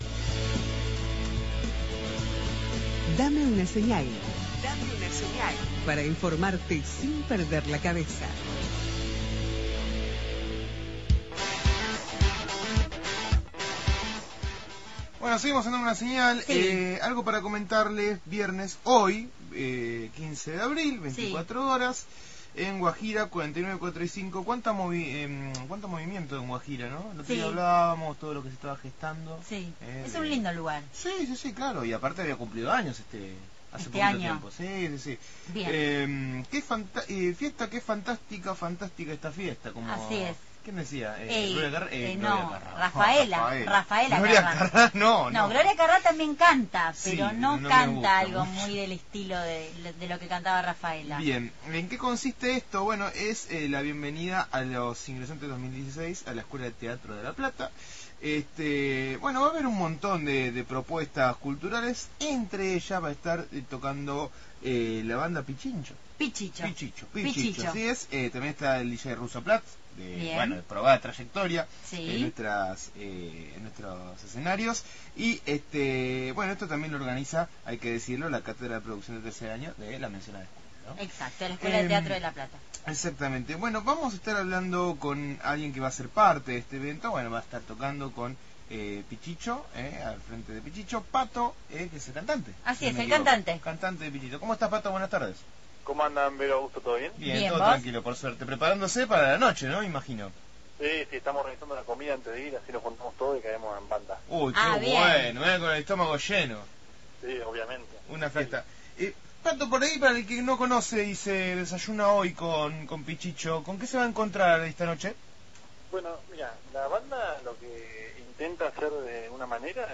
ENTREVISTA A PICHICHOS, BANDA QUE SE PRESENTARA EN LA FIESTA DE INGRESANTES DE LA ESCUELA DE TEATRO.